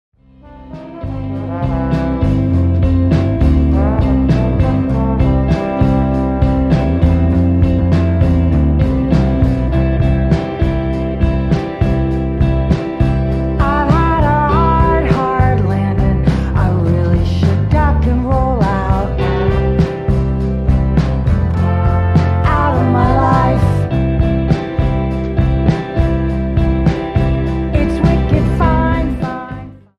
Indie / Alternativa